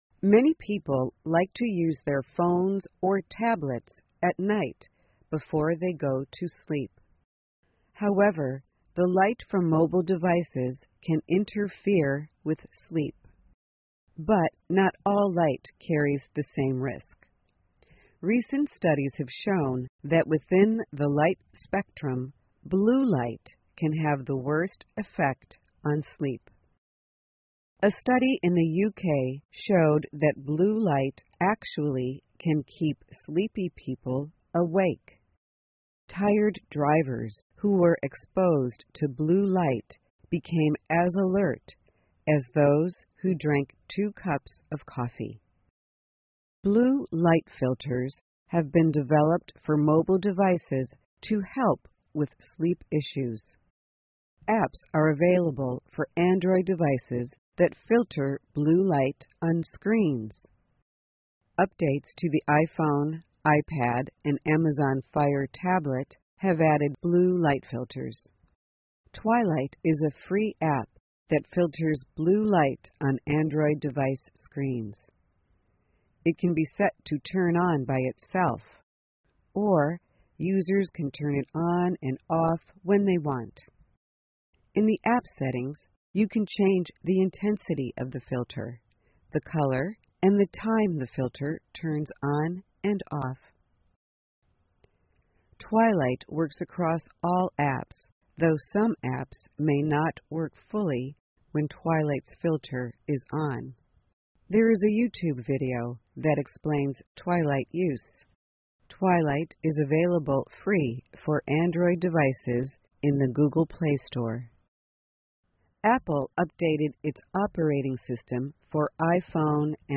VOA慢速英语2016 电子设备过滤蓝光有助更好的睡眠 听力文件下载—在线英语听力室